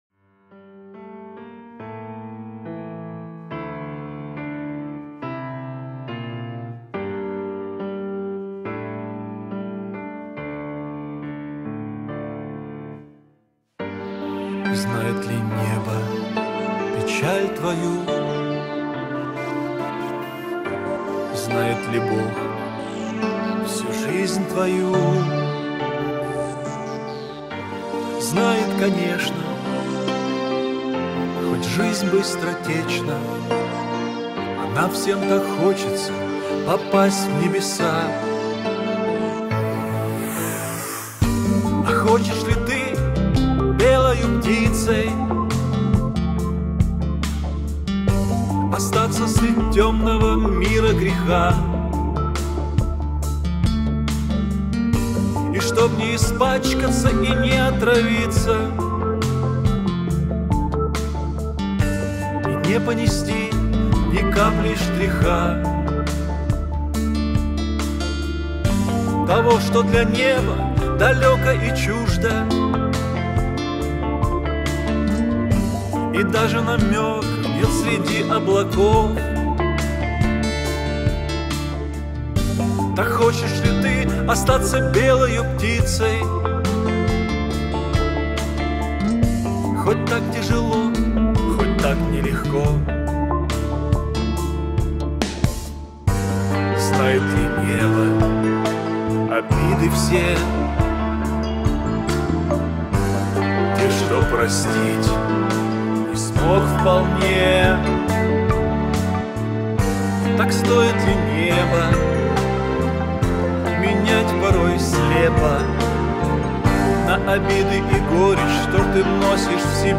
104 просмотра 78 прослушиваний 5 скачиваний BPM: 80